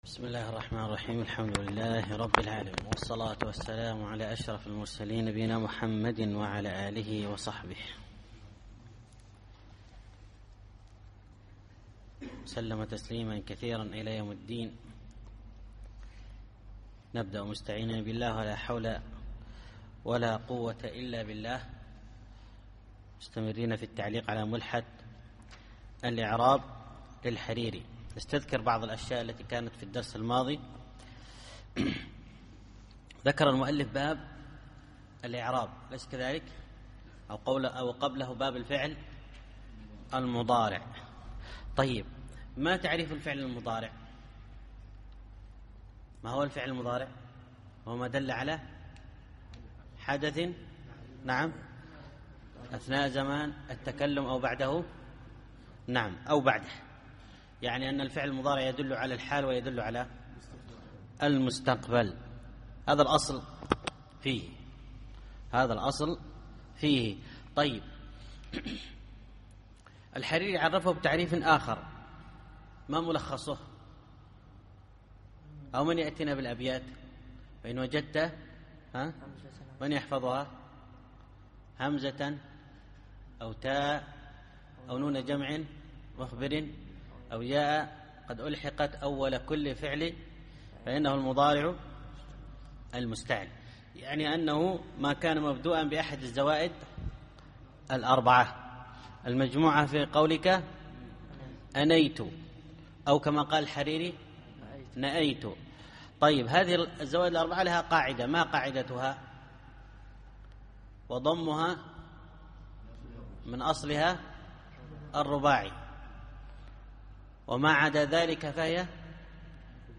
الدرس الرابع الأبيات 52-65